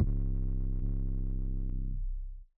BZ Redd 808.wav